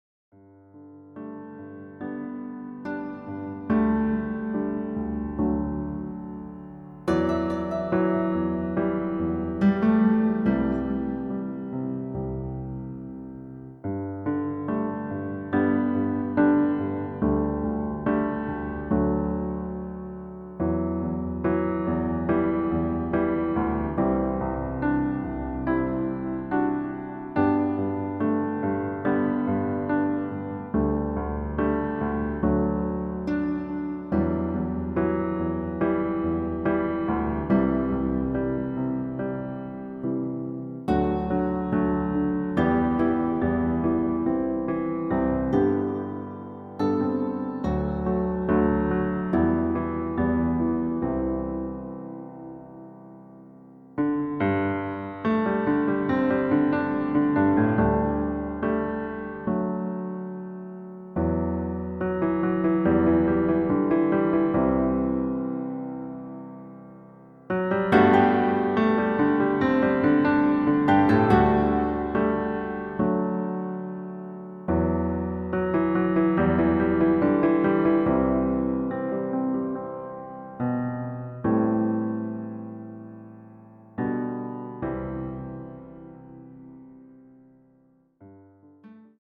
Klavierversion Flügel
• Tonart: Ab, Bb, G
• Das Instrumental beinhaltet NICHT die Leadstimme
Klavier / Streicher